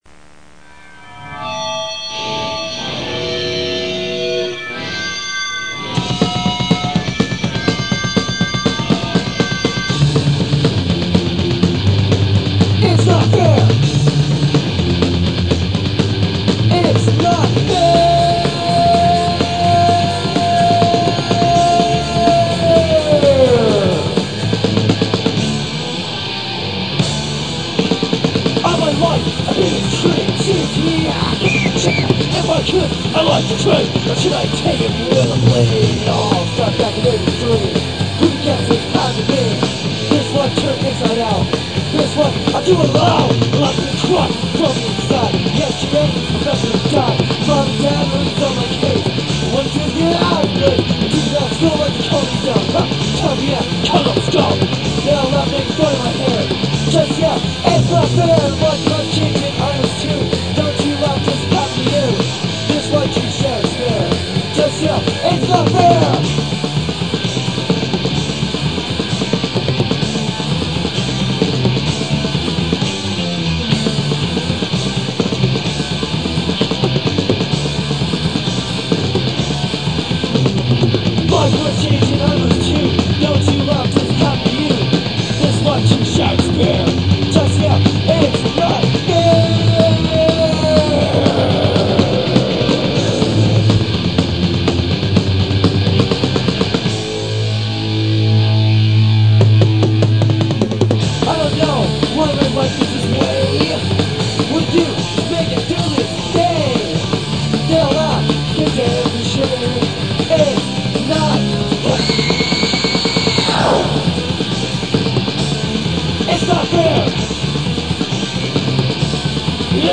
Lehigh Valley hardcore from the late 1980s/early 90s.
hardcore See all items with this value
Punk Rock Music